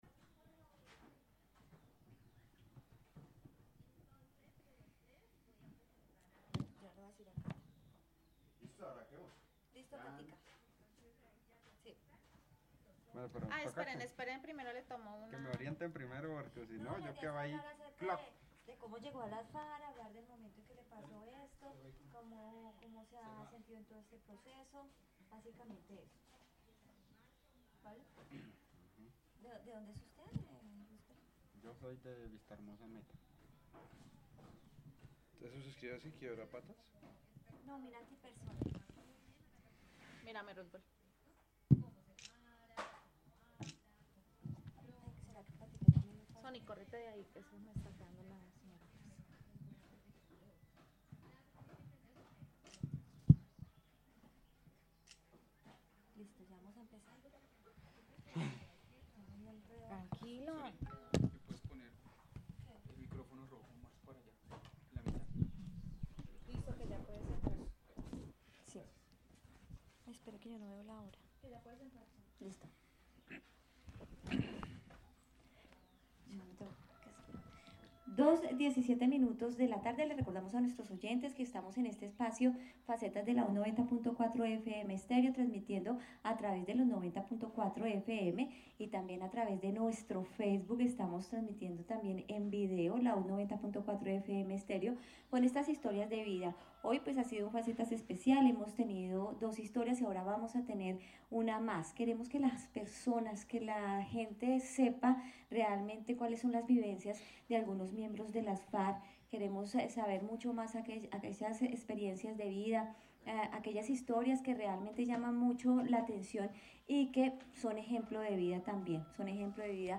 Informe radial